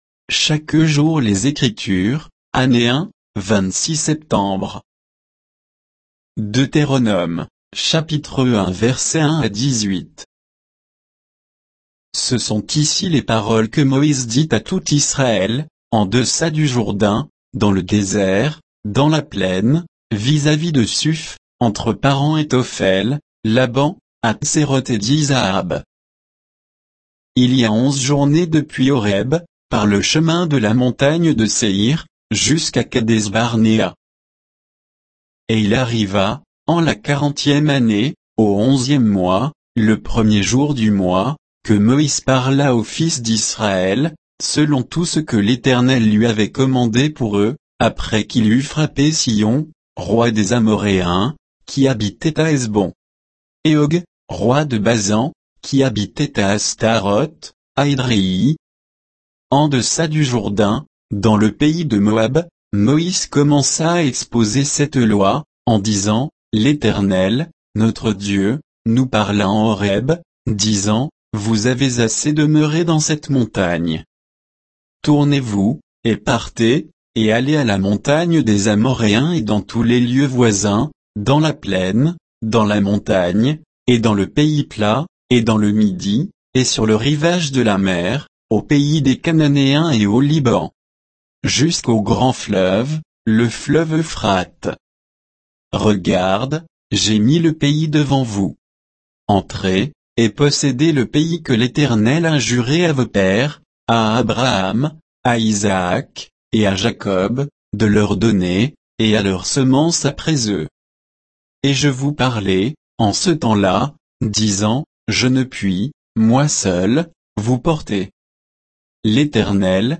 Méditation quoditienne de Chaque jour les Écritures sur Deutéronome 1